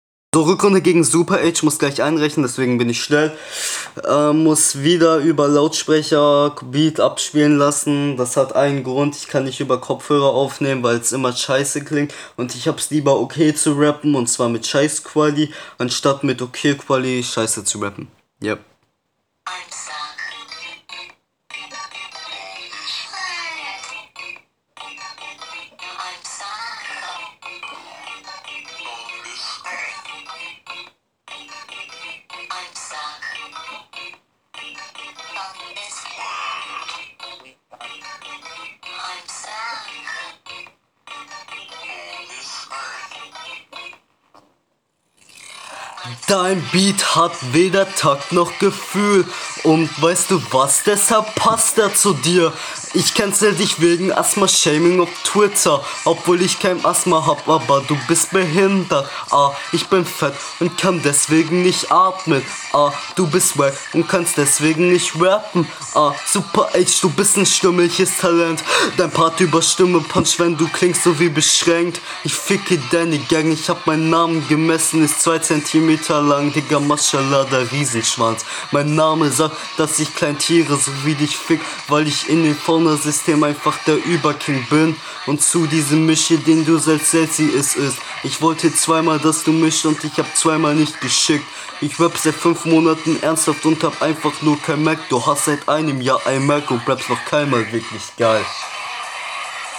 Ihr beide kommt nicht gut auf dem Beat.